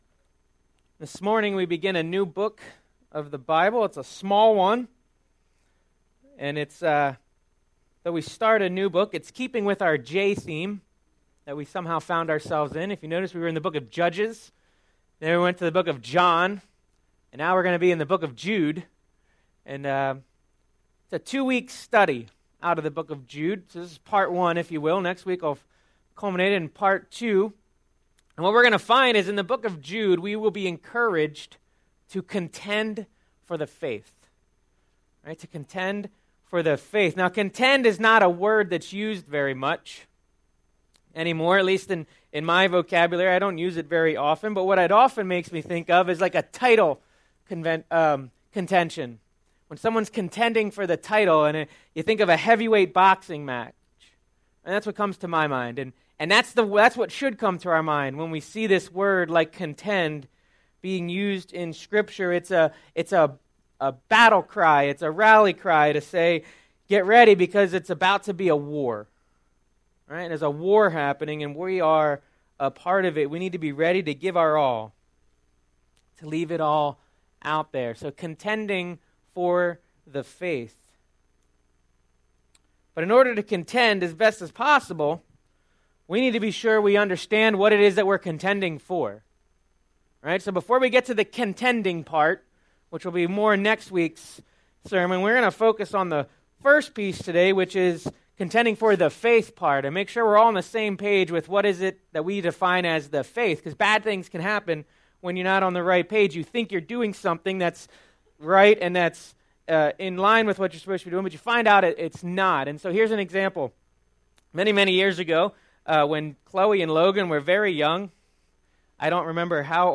Sermon Series - April 15 - Receiving God's Grace April 22 - Sharing God's Grace April 29 - Grace in Action